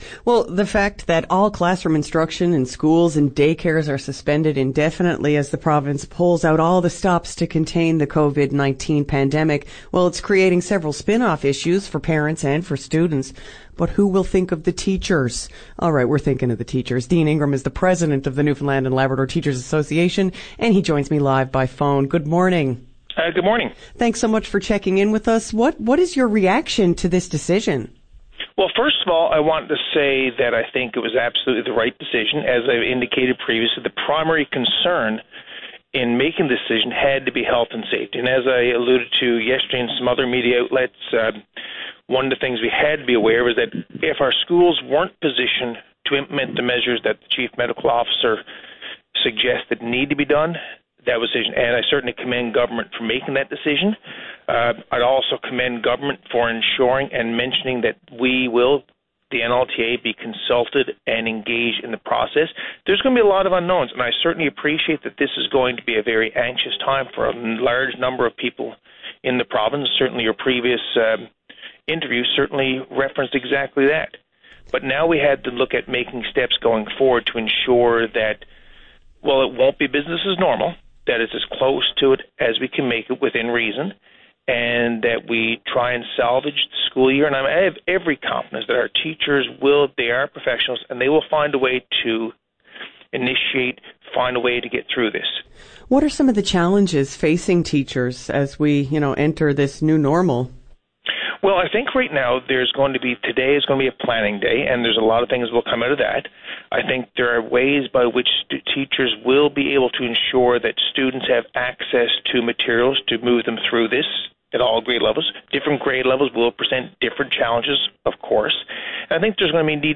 Media Interview - VOCM Morning Show March 17, 2020